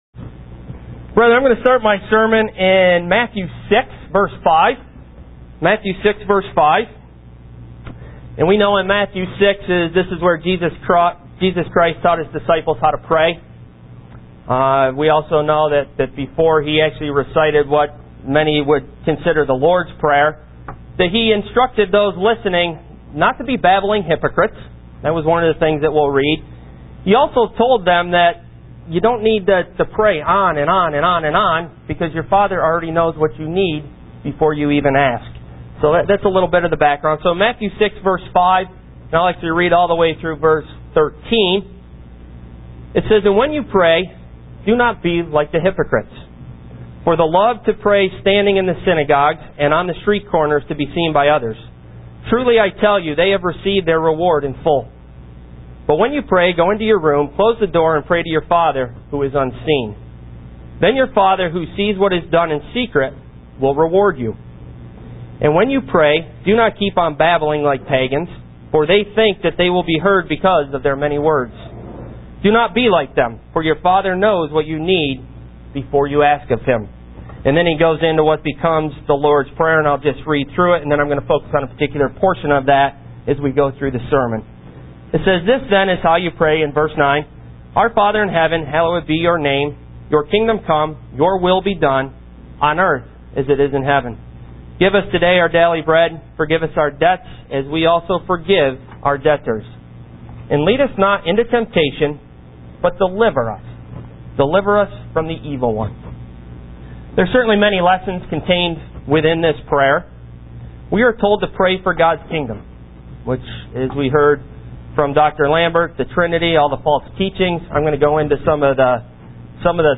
UCG Sermon Studying the bible?
Given in Buffalo, NY